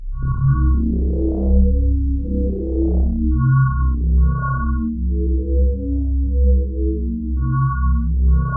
WEIRD#1.wav